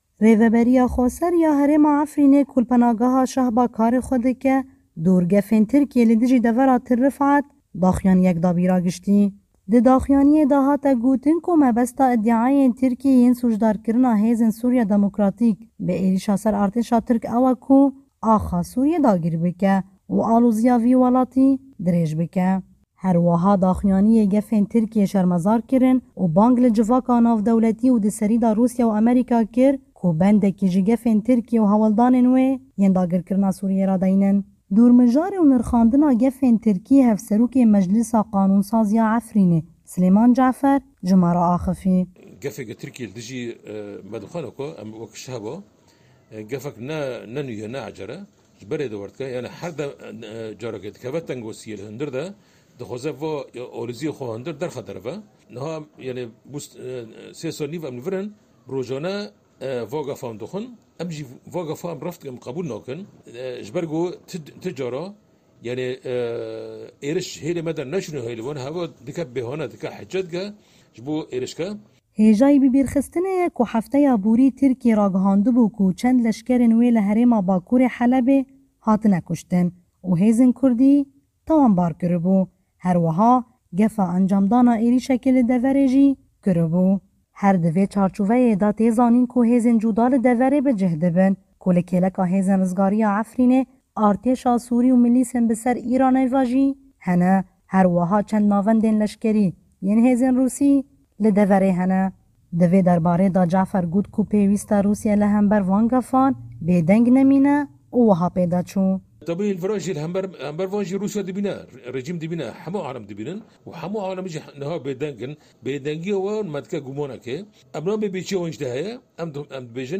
Dor mijarê û nirxandina gefên Tirkî hevserokê meclîsa qanûnsaz ya Efrînê Silêman Cefer ji me re axivî.